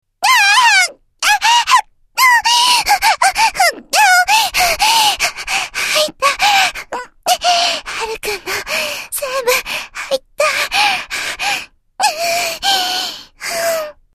サンプルボイス：　【１】　【２】